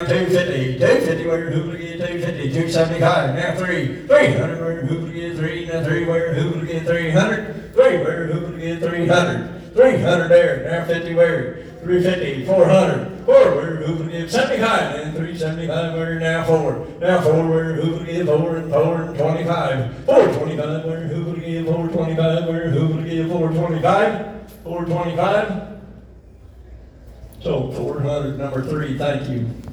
The trees that have been on public display the past week inside Flinthills Mall were auctioned Monday night.
4336-auction-live-sound.wav